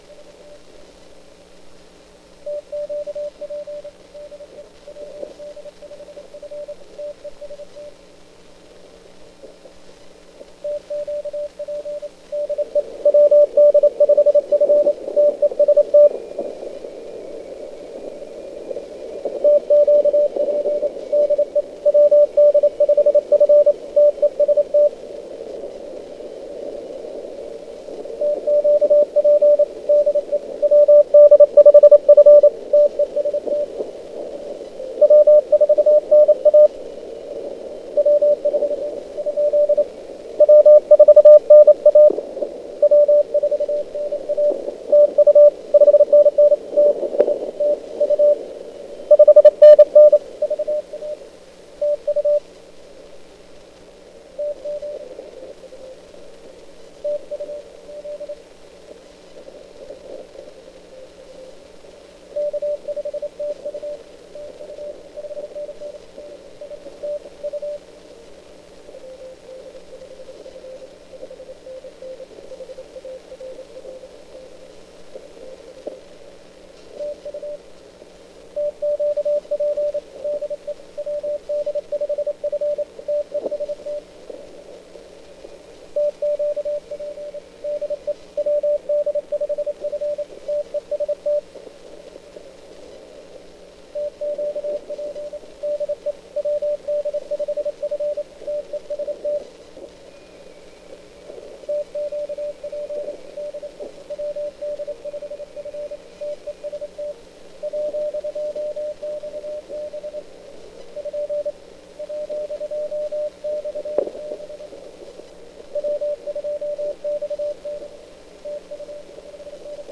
I managed to make a short 2 minute recording of the audio output from my CRX1. I don't know enough Morse code to copy what was going on, and it's going too fast for me to copy anyway. Hopefully it gives you an idea of what the CRX1 sounds like.